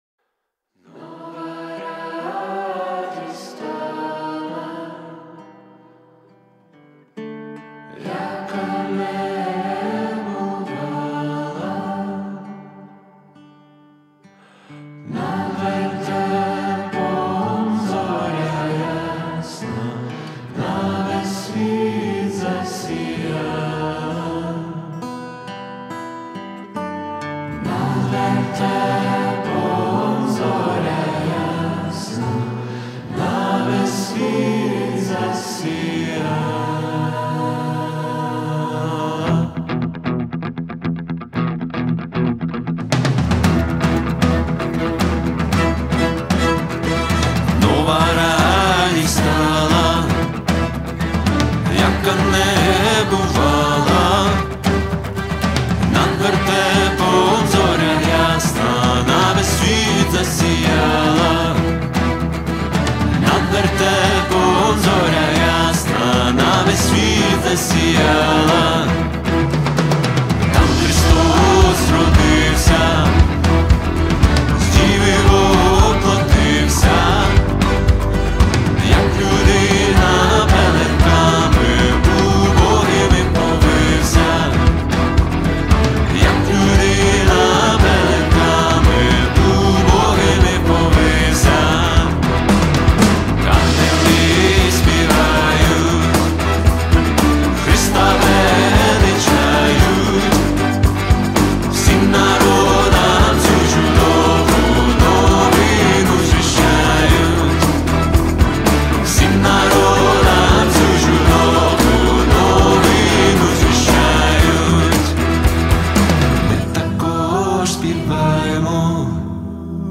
песня
407 просмотров 423 прослушивания 33 скачивания BPM: 115